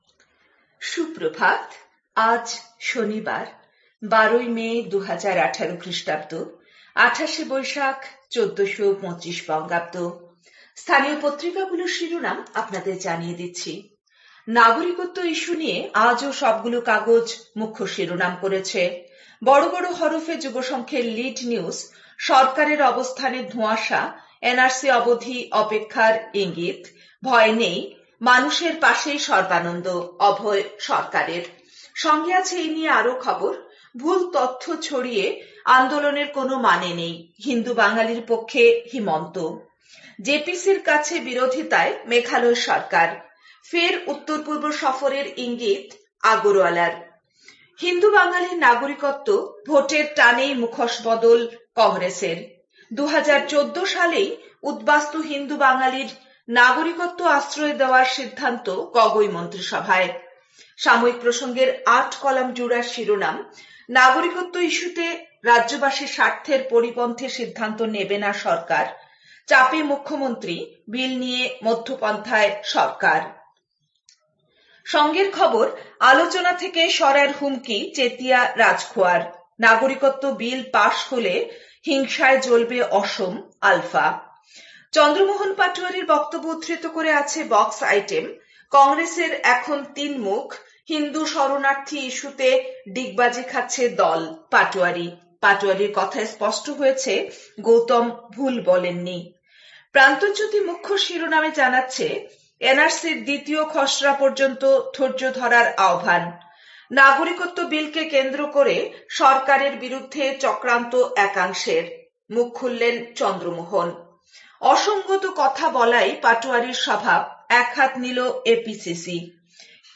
A quick bulletin with all top news.